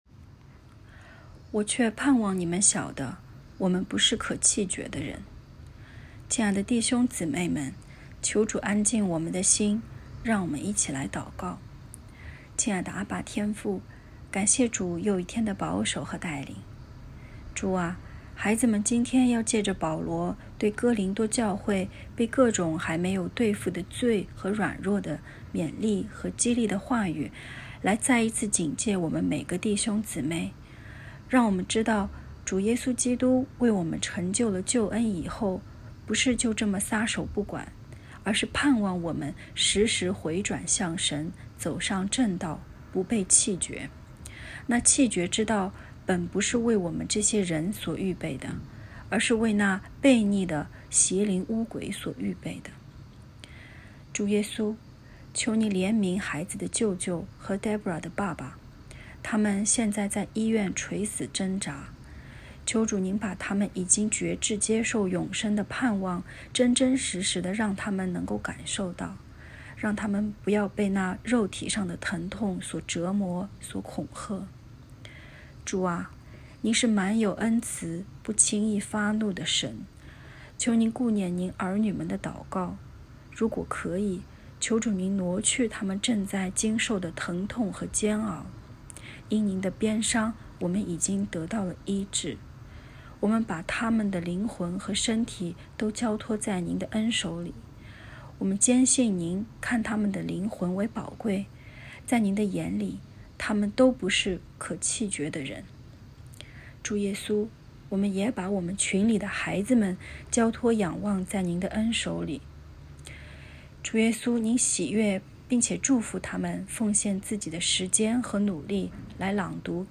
✨晚祷时间✨8月4日（周四）